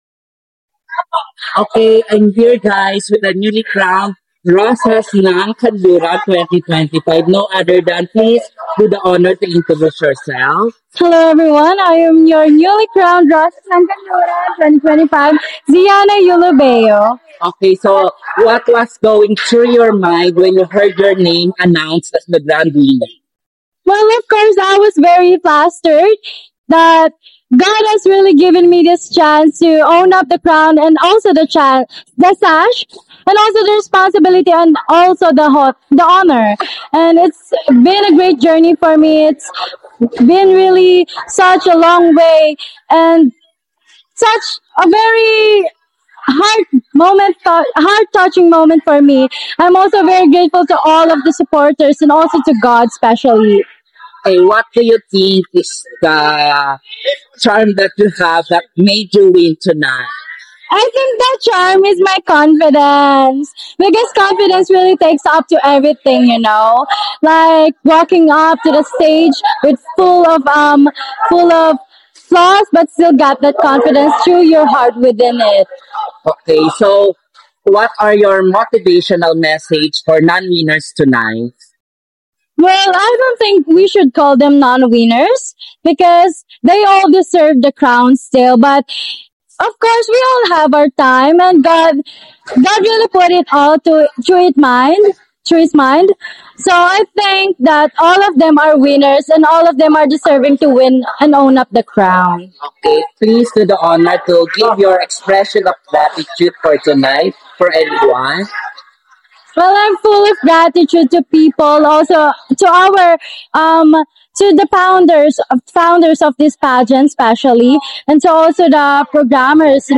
💥EXCLUSIVE INTERVIEW